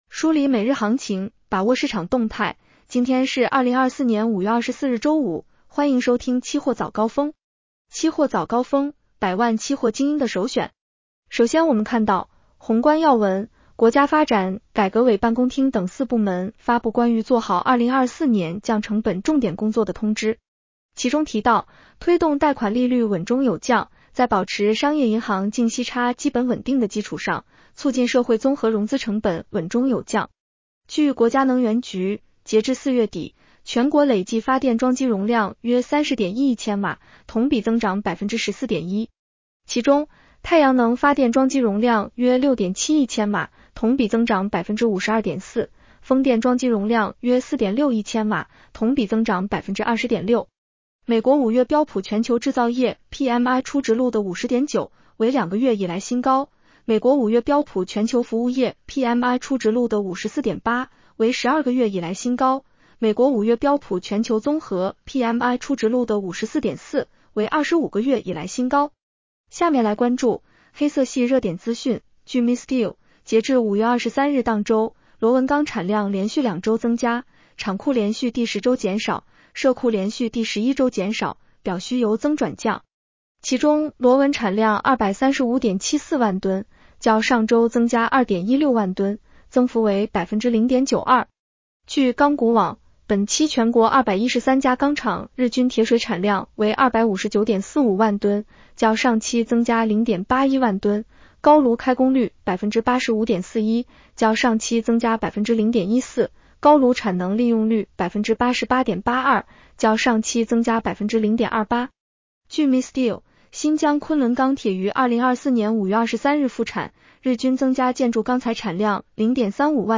期货早高峰-音频版 女声普通话版 下载mp3 宏观要闻 1.国家发展改革委办公厅等四部门发布关于做好2024年降成本重点工作的通知。